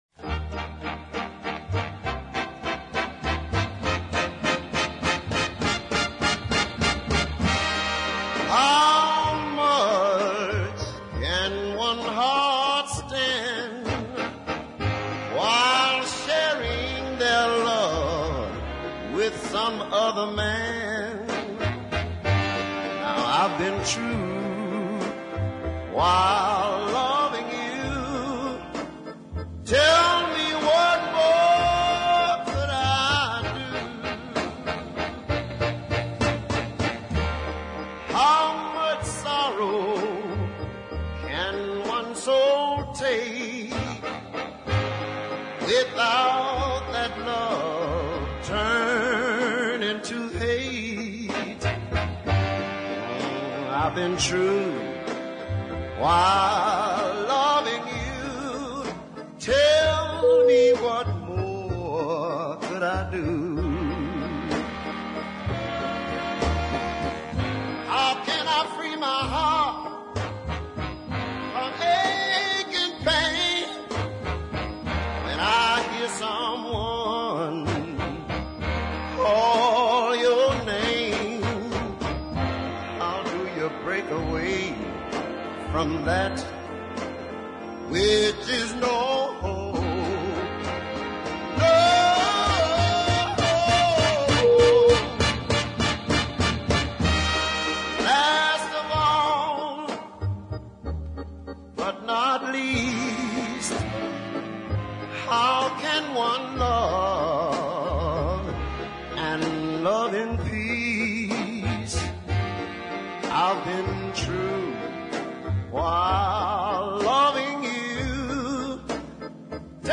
one of which was the fine blues ballad
check out his great growling finish.